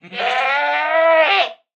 Minecraft Version Minecraft Version snapshot Latest Release | Latest Snapshot snapshot / assets / minecraft / sounds / mob / goat / screaming_pre_ram1.ogg Compare With Compare With Latest Release | Latest Snapshot
screaming_pre_ram1.ogg